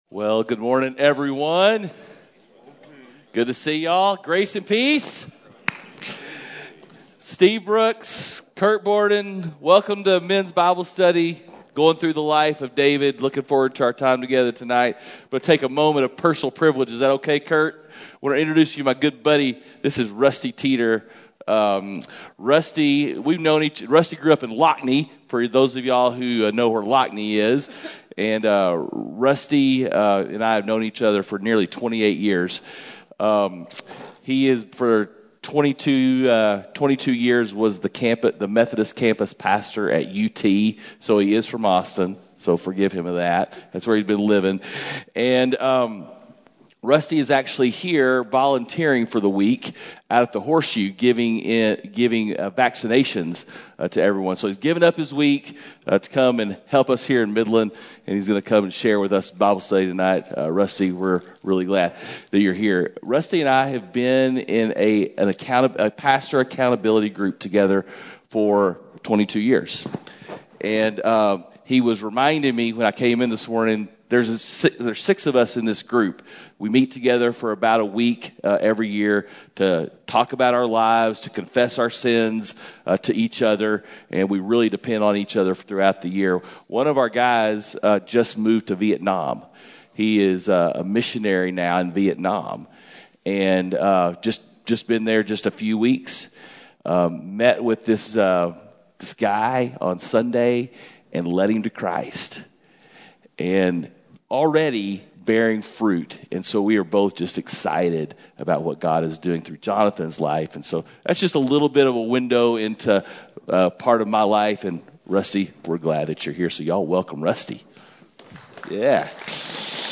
Men’s Breakfast Bible Study 2/2/21